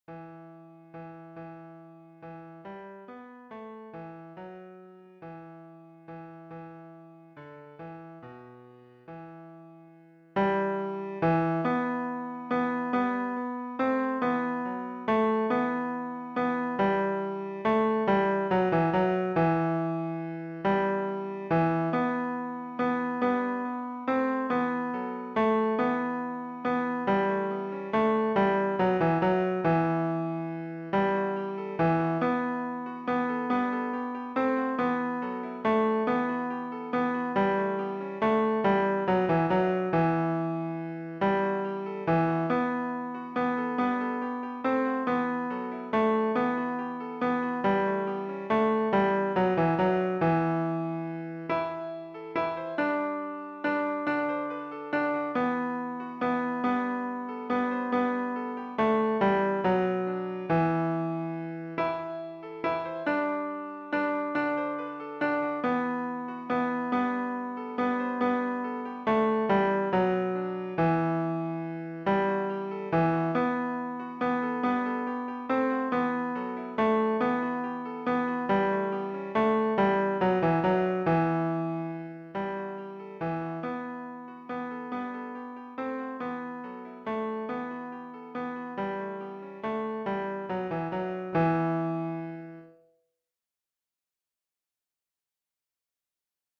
GIjtakoudkp_Tourdion-Privas-Ténor.mp3